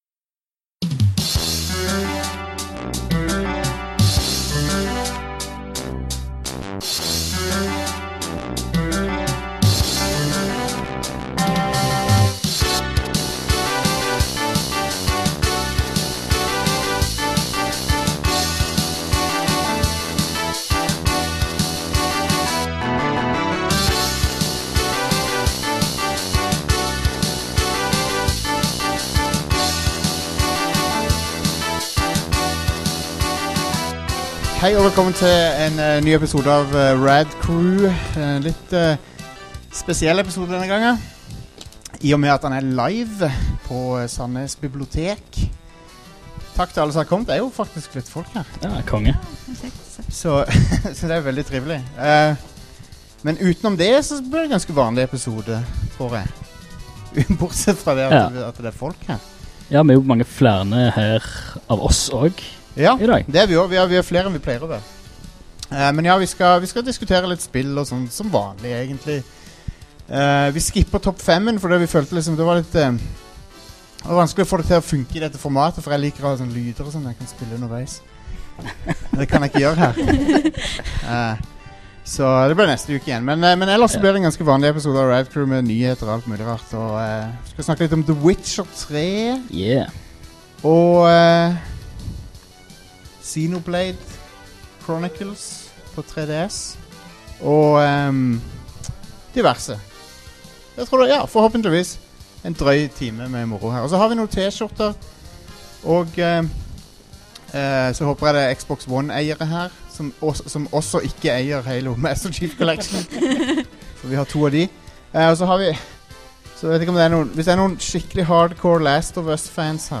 RAD CREW S09E19: Live fra Sandnes Bibliotek
Vi beklager variasjoner i volum men er det live så er det LIVE!
Vi gjestet nylig Sandnes Bibliotek med et liveshow der seks av oss hadde en liten sofaprat om spill og andre ting som opptar oss. Denne episoden er opptaket av det showet. Hovedfokuset er så klart på The Witcher 3: Wild Hunt , som flere av oss er i gang med allerede.